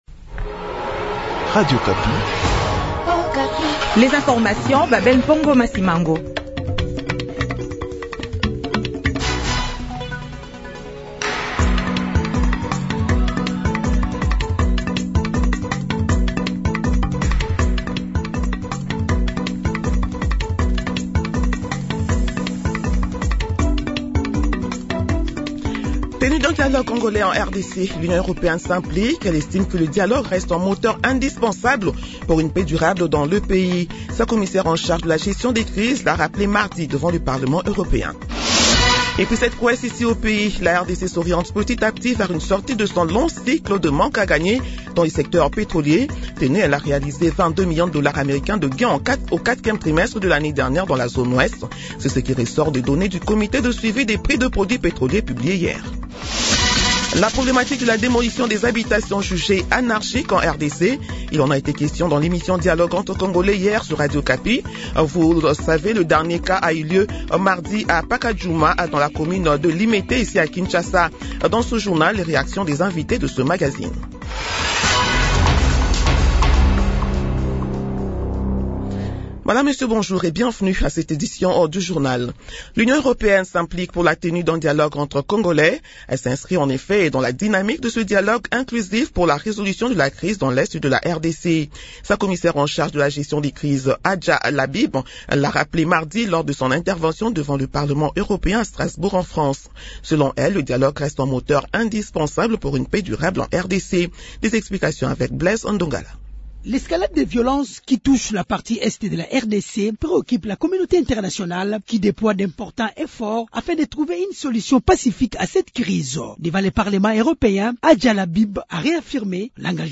Journal du matin 6 heures